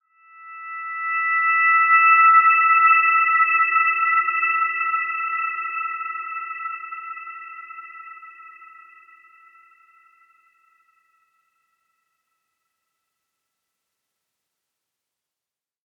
Dreamy-Fifths-E6-f.wav